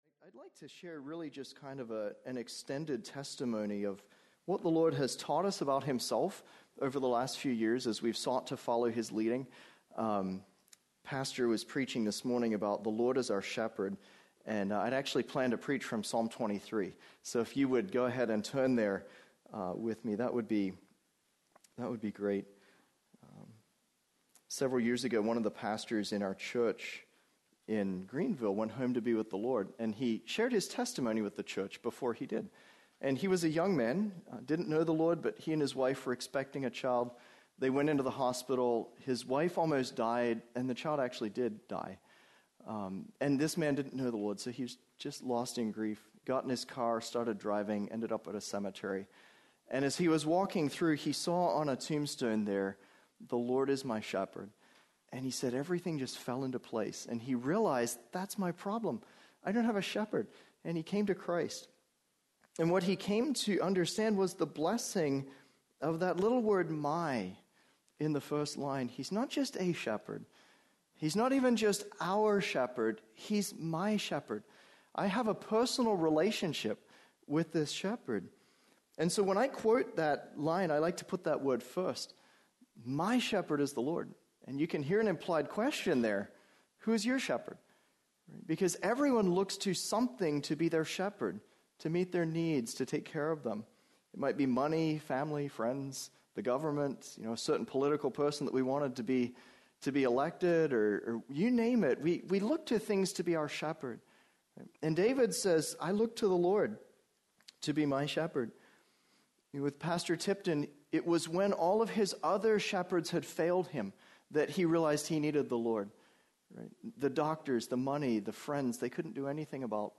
We trust you will be encouraged by the preaching and teaching ministry of Heritage Baptist Church in Windham, NH.